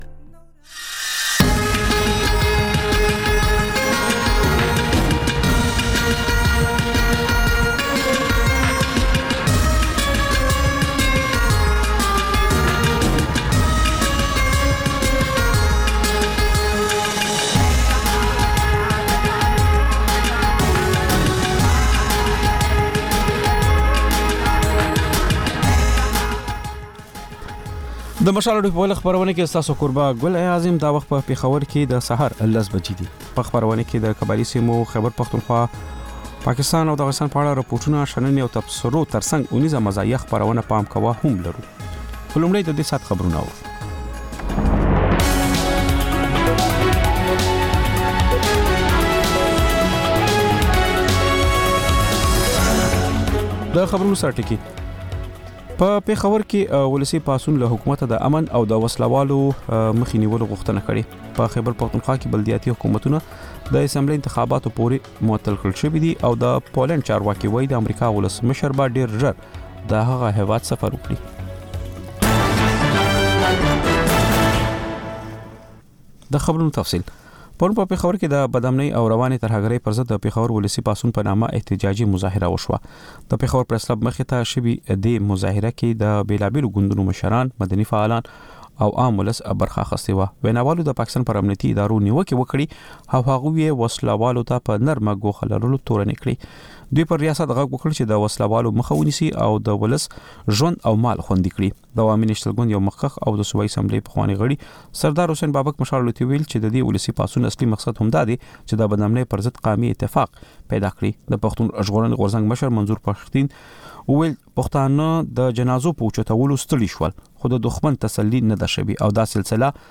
په دې خپرونه کې تر خبرونو وروسته بېلا بېل رپورټونه، شننې او تبصرې اورېدای شﺉ. د خپرونې په وروستیو پینځلسو دقیقو یا منټو کې یوه ځانګړې خپرونه خپرېږي.